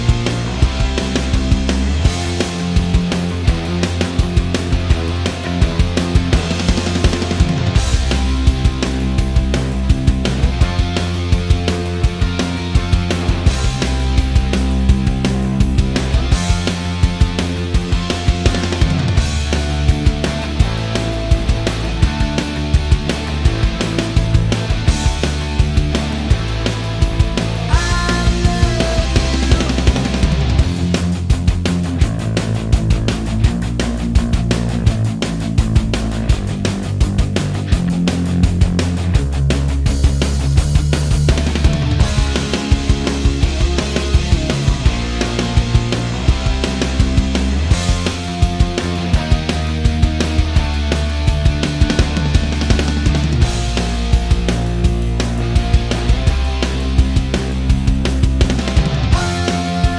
no Backing Vocals Punk 2:22 Buy £1.50